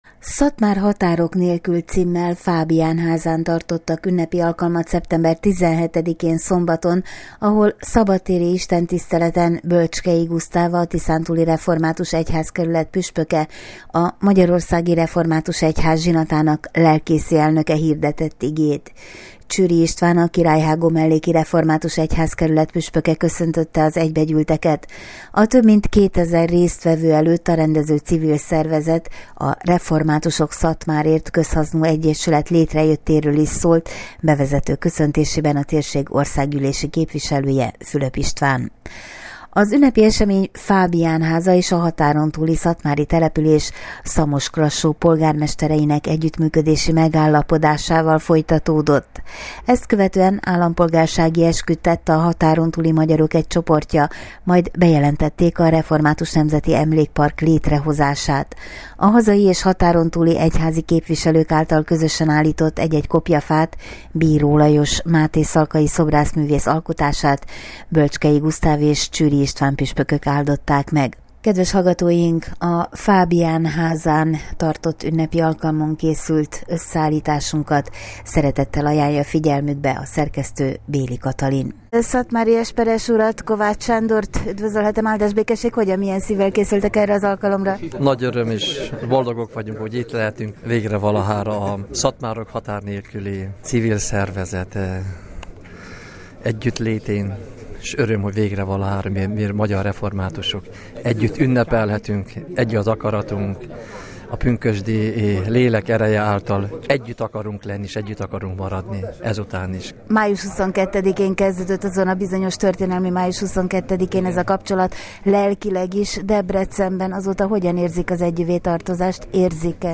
A több mint kétezer résztvevő előtt a rendező civil szervezet, a Reformátusok Szatmárért Közhasznú Egyesület létrejöttéről is szólt bevezető köszöntésében a térség országgyűlési képviselője.